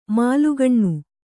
♪ mālugaṇṇu